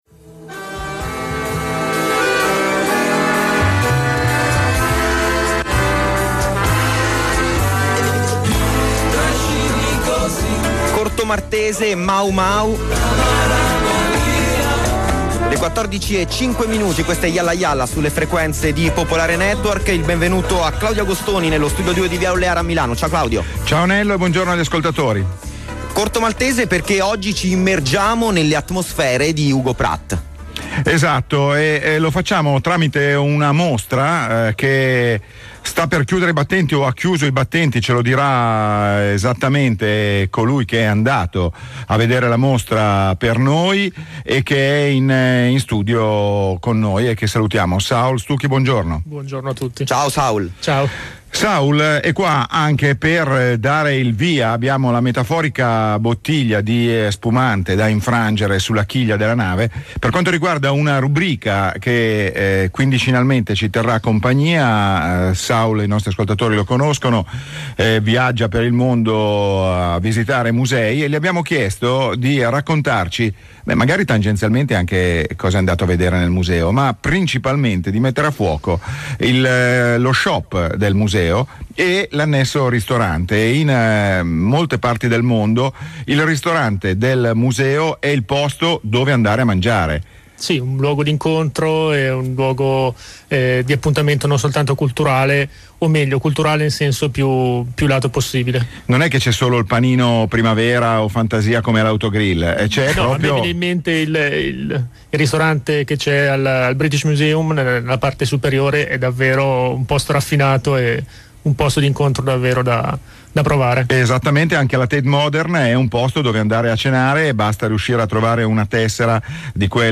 Giovedì scorso, 29 settembre, la trasmissione radiofonica Jall! Jalla! di Radio Popolare si è aperta con la recensione della mostra Hugo Pratt.